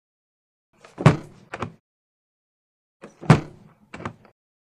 Prop Plane; Hatch Close; Mosquito Prop Aircraft Hatch Closing.